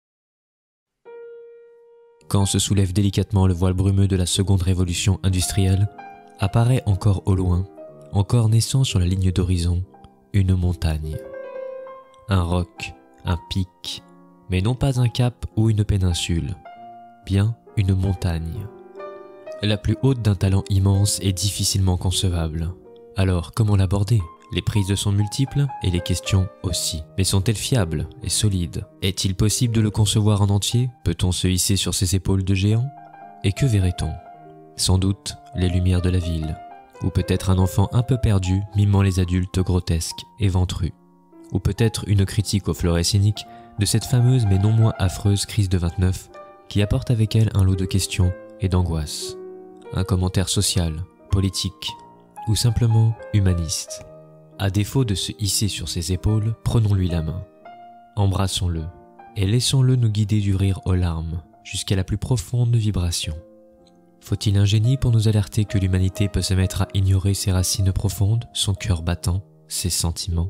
Intro narrative